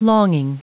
Prononciation du mot longing en anglais (fichier audio)
Prononciation du mot : longing